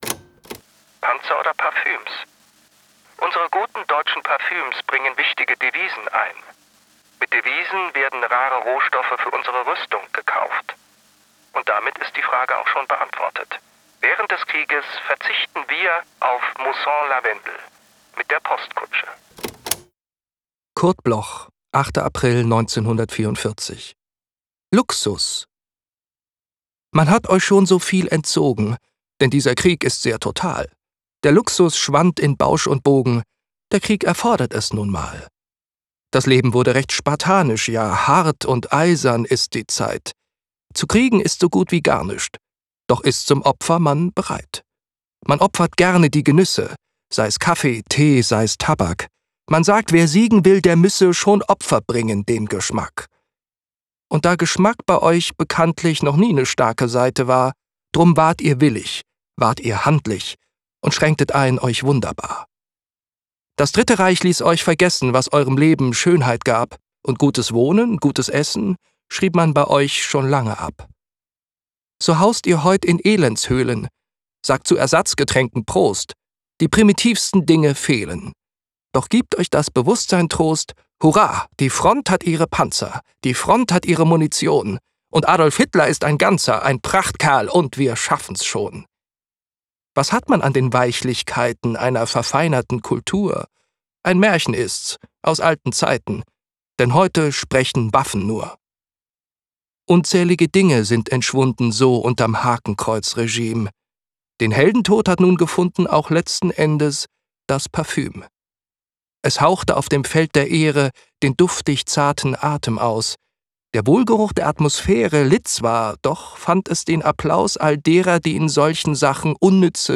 vorgetragen von